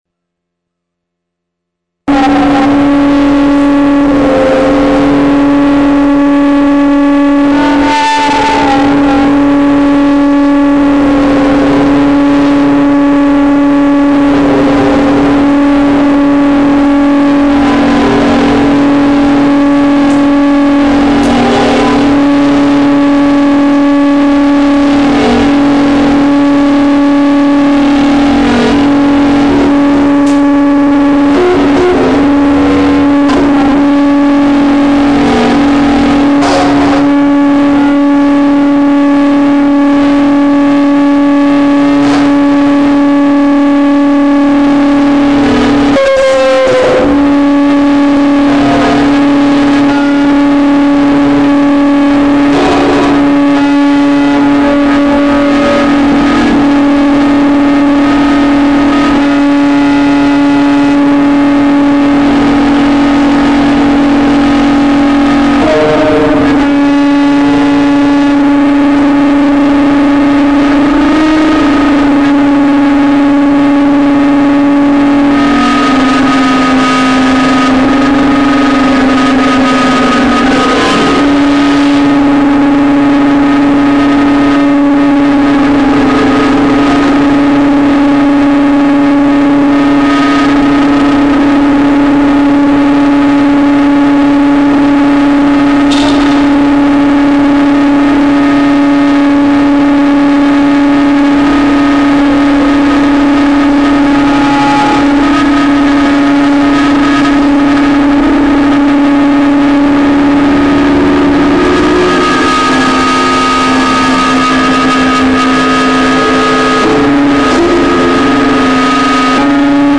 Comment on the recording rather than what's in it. recorded Eyedrum 12/14/02